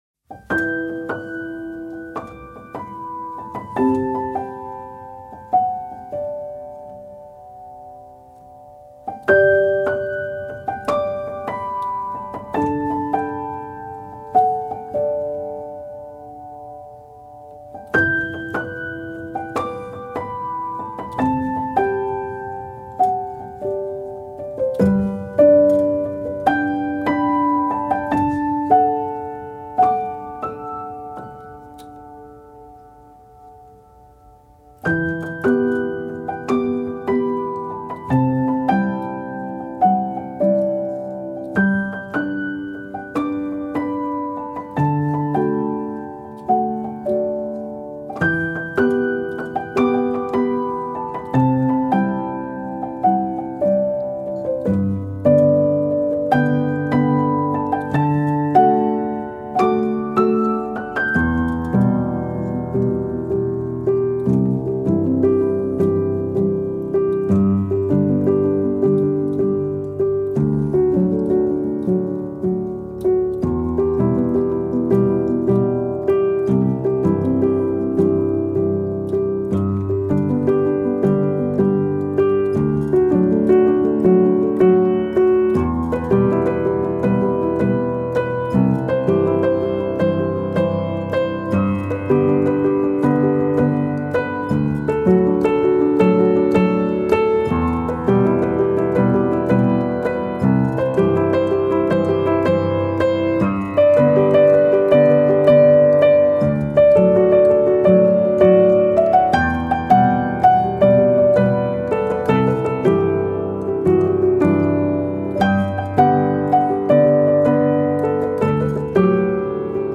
سبک آرامش بخش , پیانو , مدرن کلاسیک , موسیقی بی کلام
پیانو آرامبخش